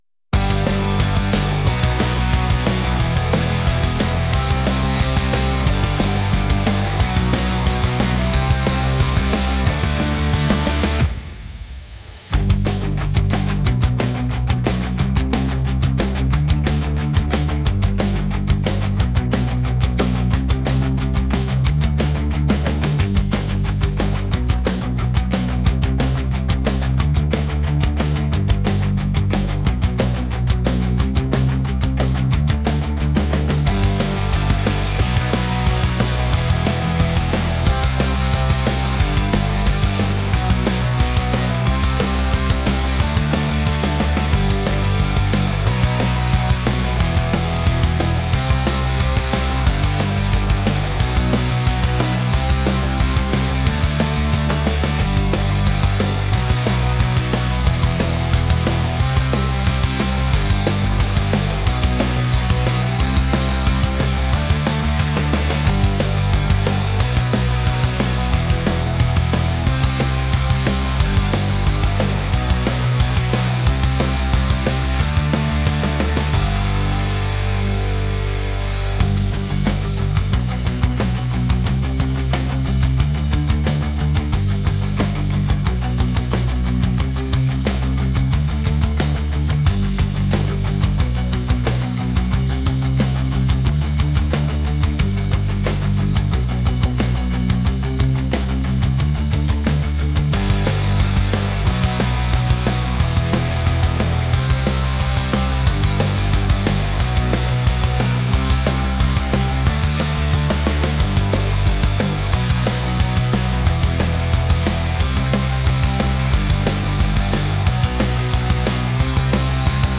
rock | catchy | energetic